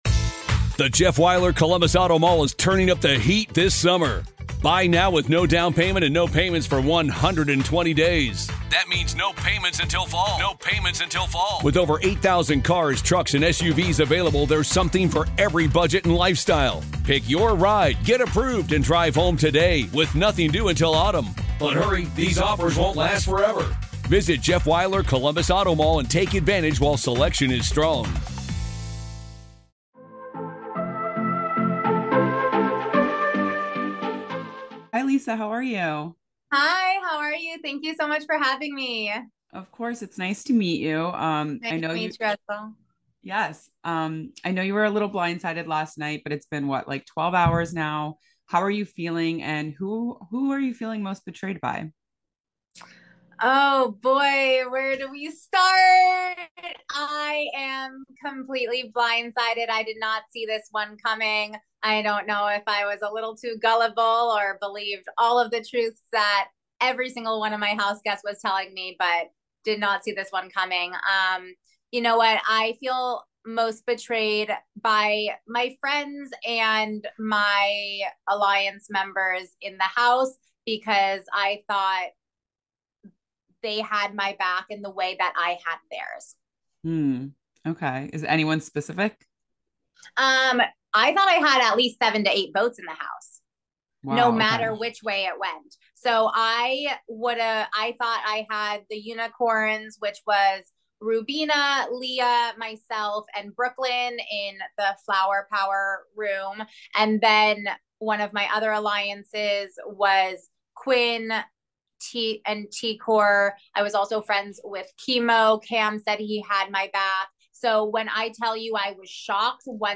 exit interview